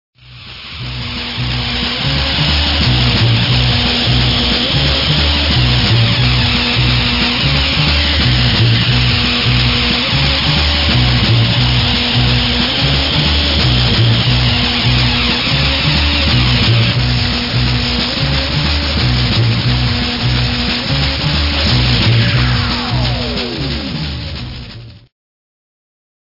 サイケデリック・電子・モンド・ミュージック・ユニット